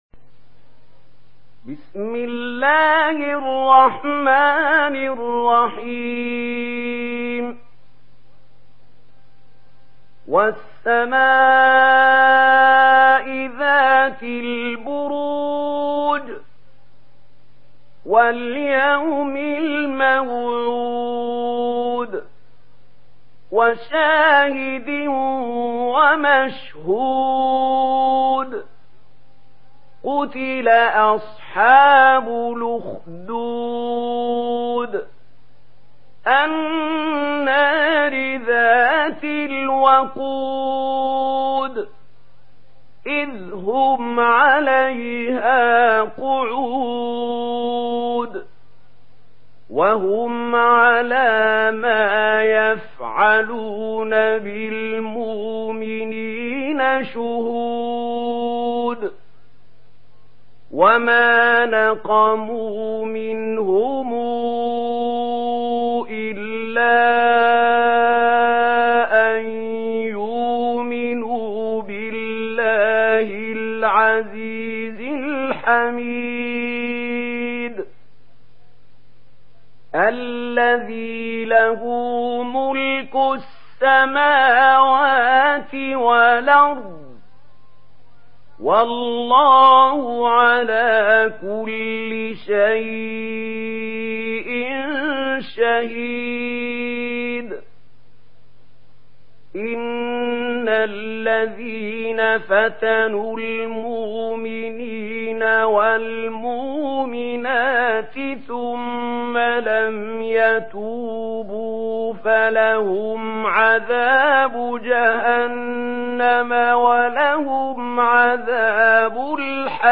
Surah Al-Buruj MP3 in the Voice of Mahmoud Khalil Al-Hussary in Warsh Narration
Surah Al-Buruj MP3 by Mahmoud Khalil Al-Hussary in Warsh An Nafi narration.
Murattal Warsh An Nafi